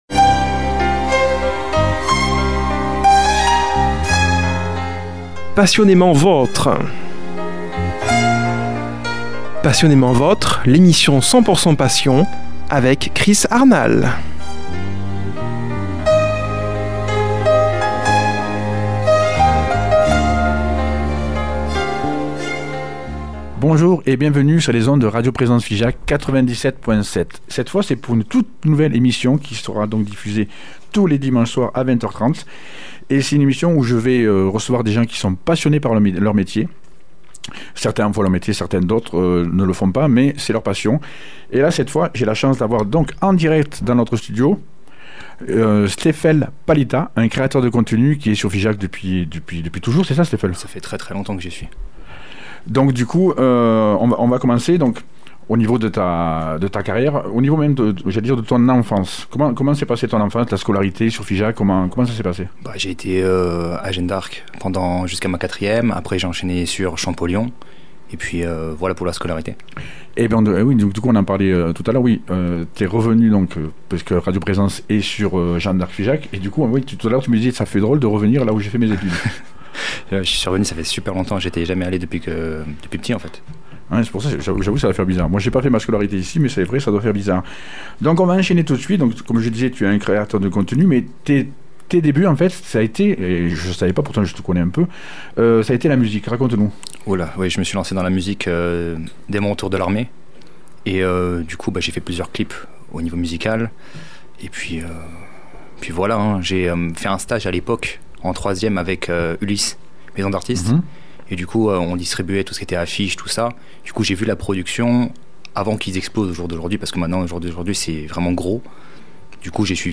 reçoit au studio comme invité